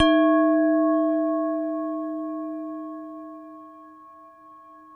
WHINE  D#2-R.wav